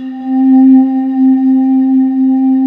Index of /90_sSampleCDs/USB Soundscan vol.28 - Choir Acoustic & Synth [AKAI] 1CD/Partition D/19-IDVOX FLT